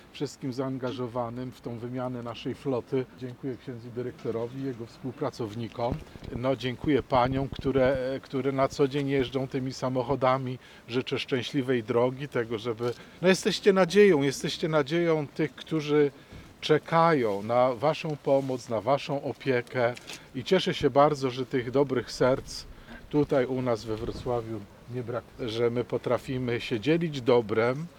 Jesteście nadzieją tych, którzy czekają na Waszą pomoc i opiekę – tymi słowami zwrócił się do pracowników Hospicjum Domowego abp Józef Kupny.